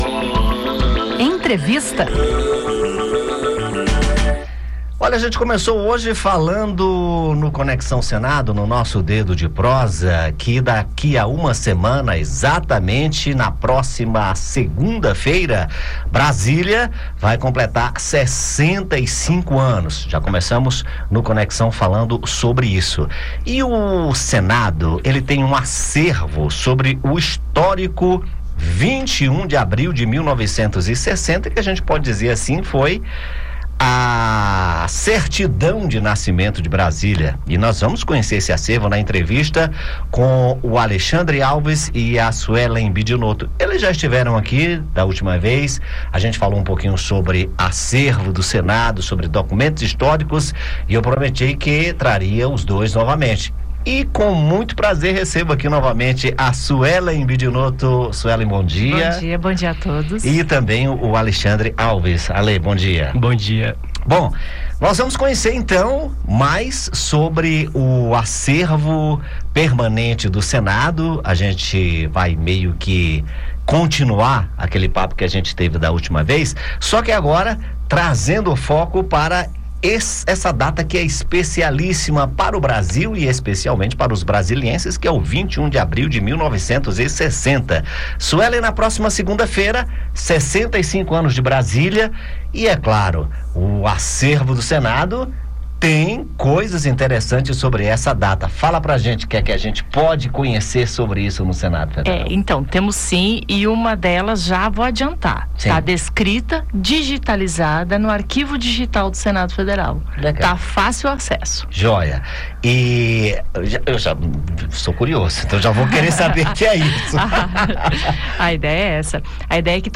Para marcar a data, o Arquivo do Senado destaca registros históricos que revelam os bastidores e os momentos marcantes do dia da inauguração da nova capital, em 1960. Em entrevista